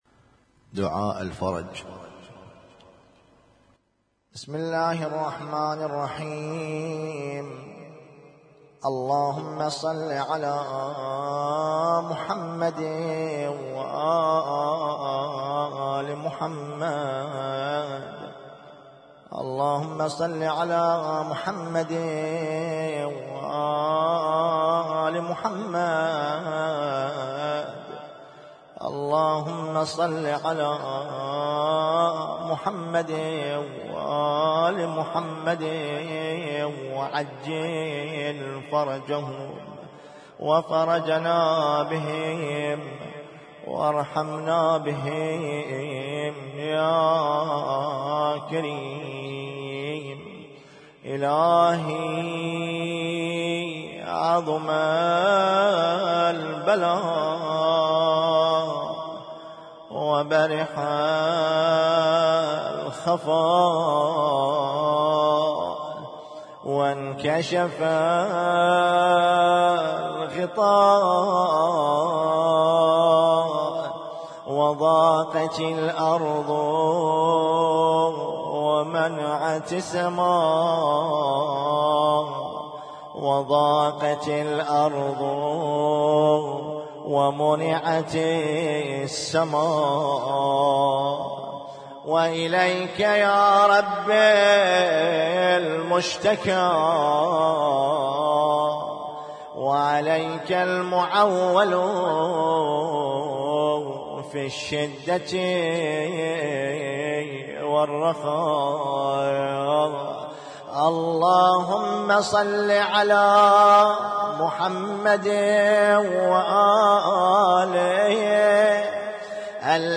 اسم التصنيف: المـكتبة الصــوتيه >> الادعية >> الادعية المتنوعة